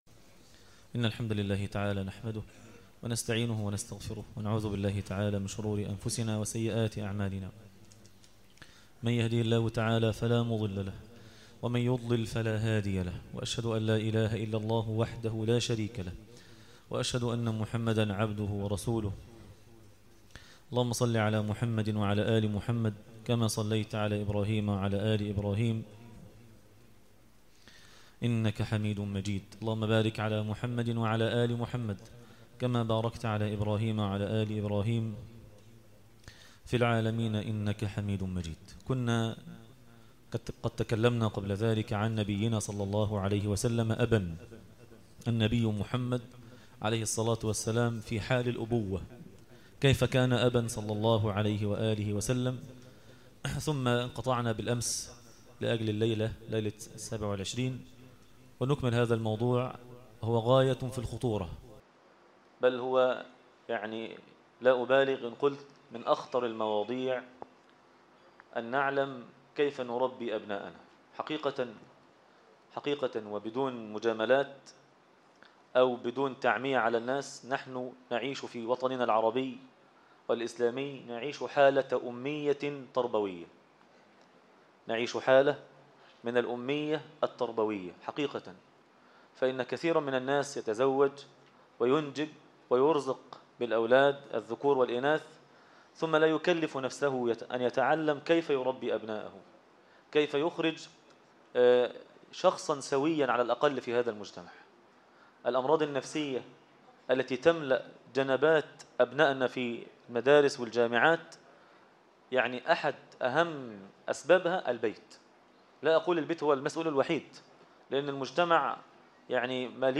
النبي (صلي الله عليه وسلم ) مربيا - درس التراويح ليلة 28 رمضان 1437هـ